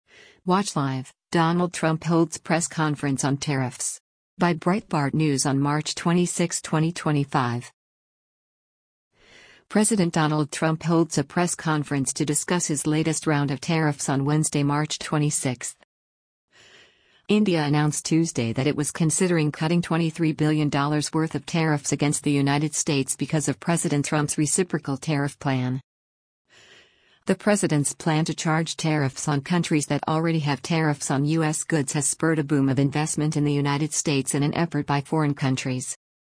President Donald Trump holds a press conference to discuss his latest round of tariffs on Wednesday, March 26.